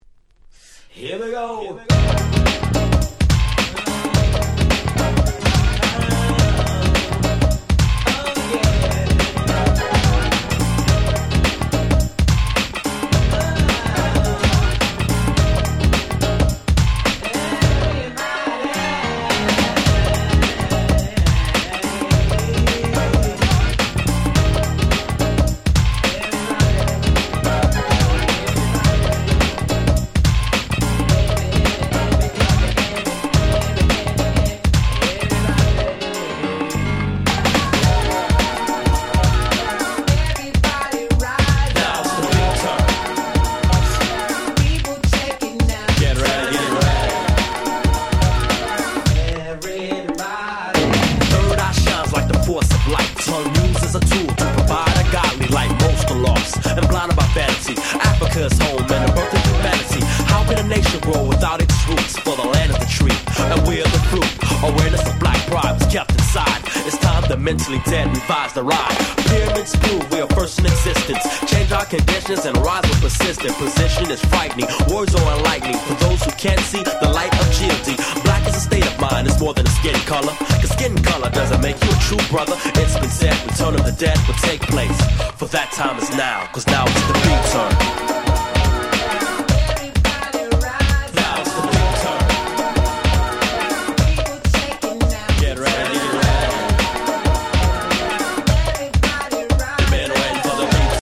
90' Smash Hit R&B / New Jack Swing / Rap !!
当時はここ日本のClub, Discoでも大ヒットしたニュージャックスウィングナンバー！
バッキバキにハネたダンサー受け抜群の必殺の1曲！